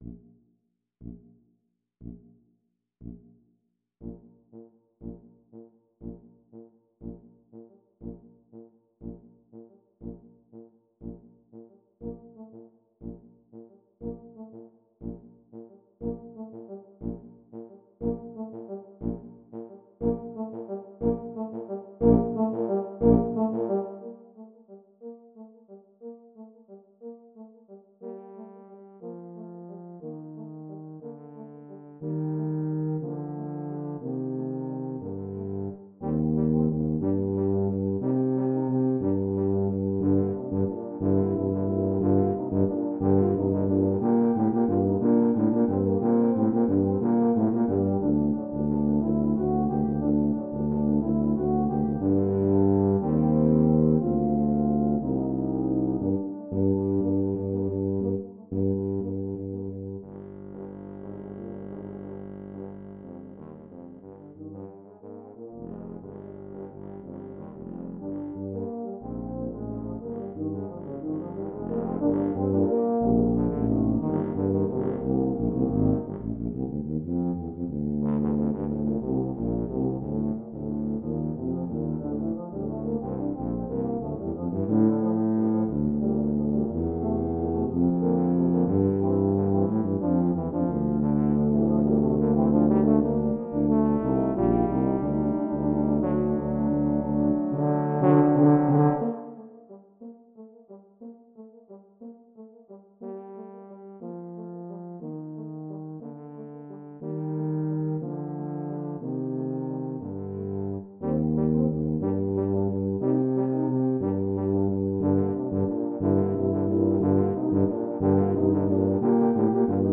Voicing: Tuba / Euphonium Quartet